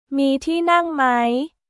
ミー ティー ナング マイ?